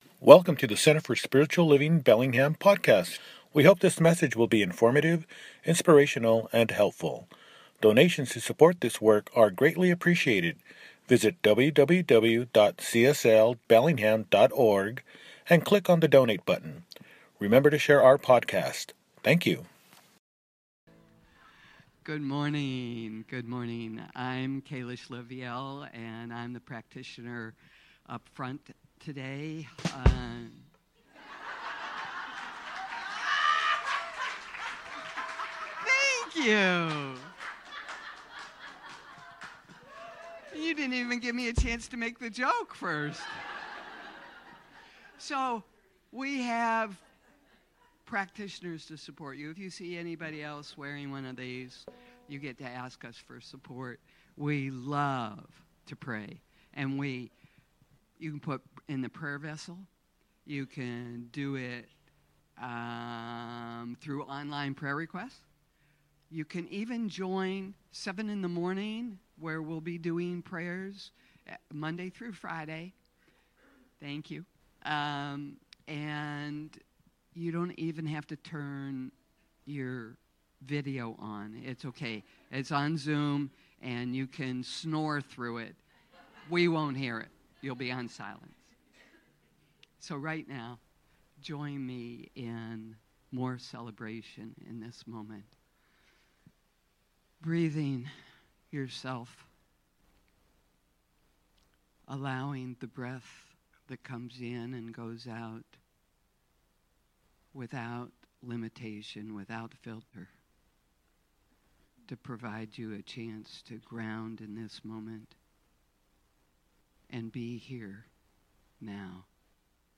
My Garden, My Choice – Celebration Service